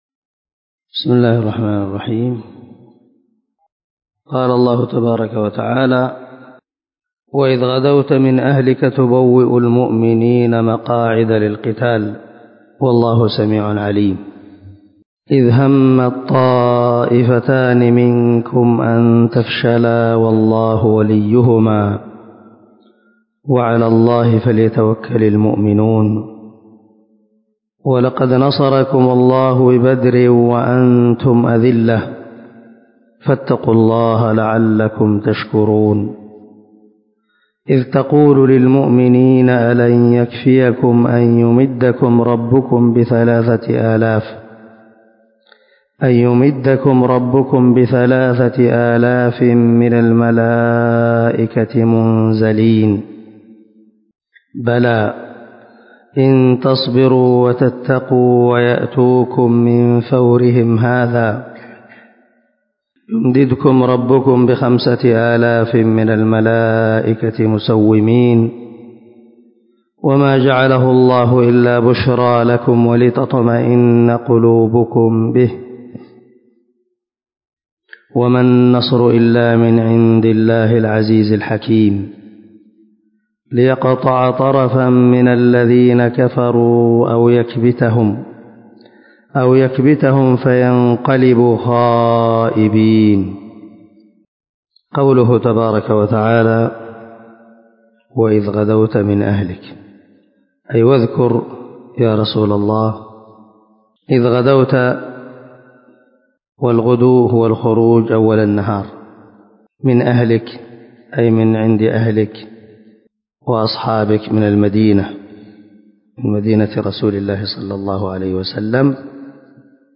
195الدرس 40 تفسير آية ( 121 – 127 ) من سورة آل عمران من تفسير القران الكريم مع قراءة لتفسير السعدي